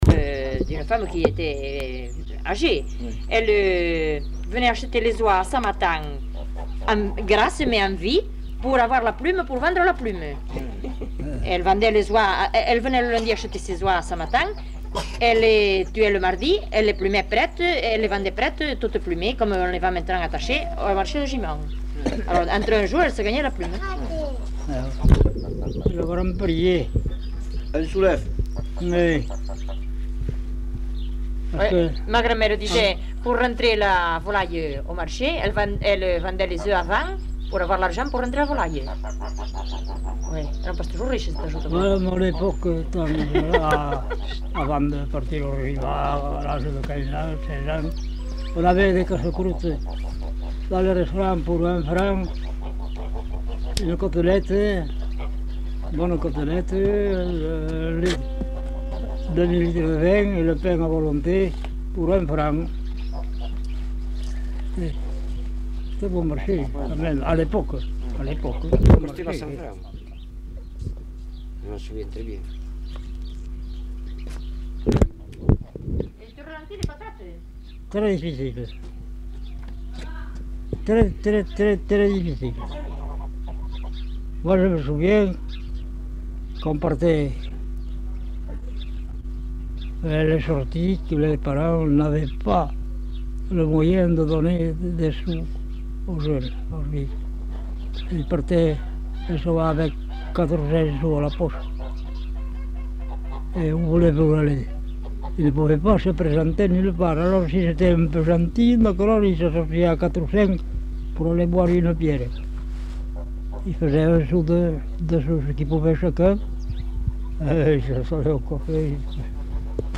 Lieu : Samatan
Genre : témoignage thématique